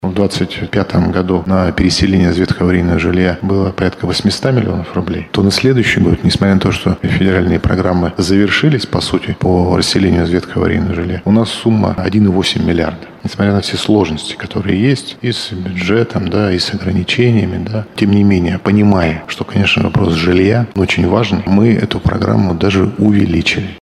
Финансирование увеличили в 2 раза, несмотря на завершение федеральной программы, — рассказал губернатор Денис Паслер во время прямой линии.